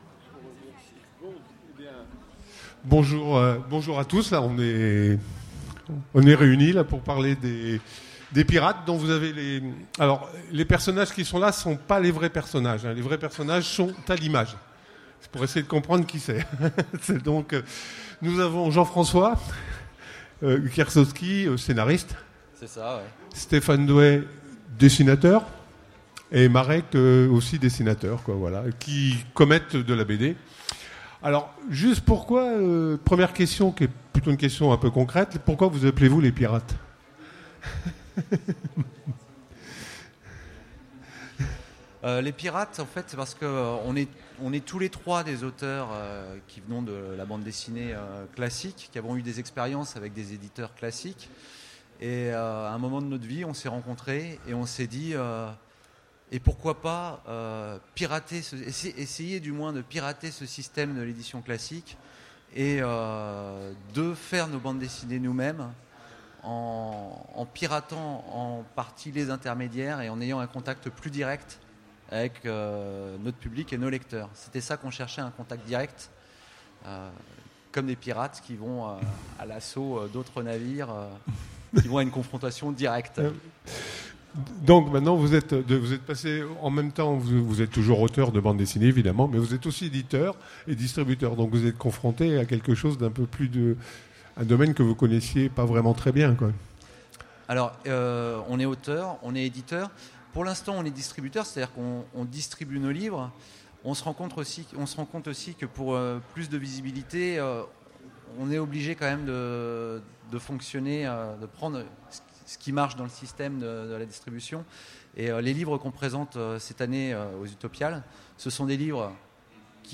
Utopiales 2015 : Conférence La Suite de Skolem